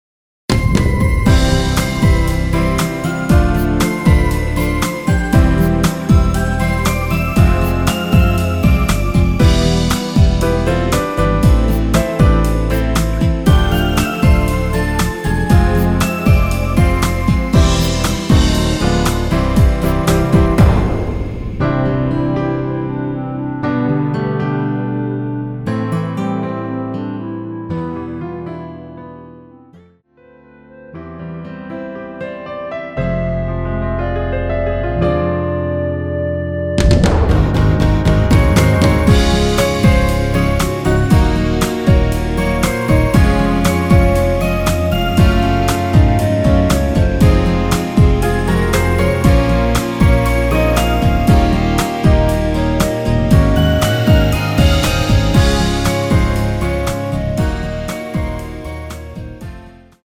(-3) 내린 멜로디포힘된 MR 입니다.
◈ 곡명 옆 (-1)은 반음 내림, (+1)은 반음 올림 입니다.
앞부분30초, 뒷부분30초씩 편집해서 올려 드리고 있습니다.
중간에 음이 끈어지고 다시 나오는 이유는